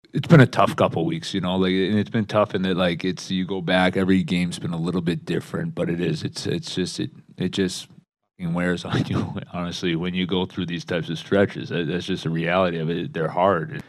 Muse says the Pens worked hard to finally break the losing streak.